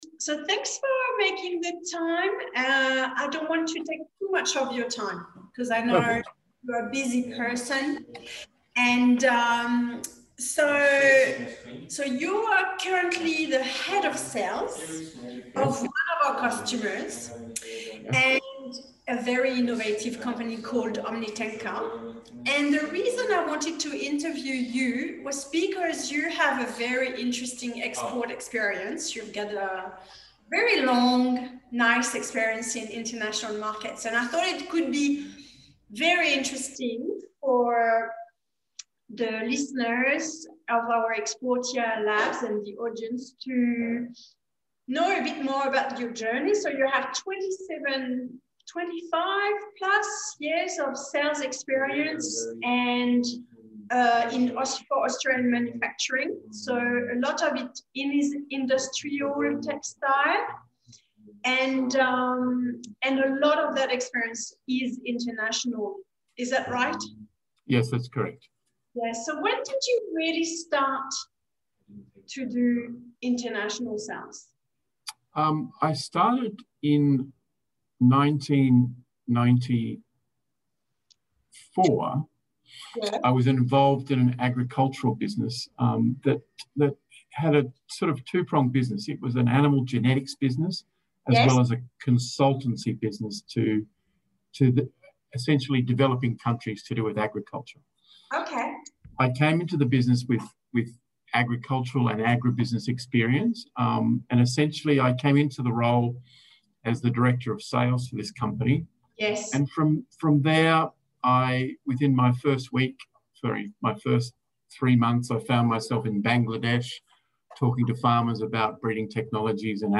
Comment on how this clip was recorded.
The interview has been slightly edited to ease the read.